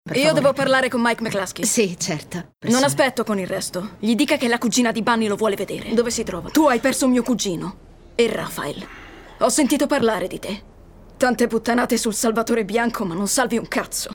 nel telefilm "Mayor of Kingstown"